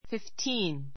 fifteen 小 A1 fiftíːn ふィ ふ ティ ーン 名詞 複 fifteens fiftíːnz ふィ ふ ティ ーン ズ ❶ 15 ; 15歳 さい ❷ 15人[個]一組のもの, ラグビーチーム 形容詞 15の ; 15人[個]の; 15歳で fifteen students fifteen students 15人の学生 It's fifteen minutes past ten.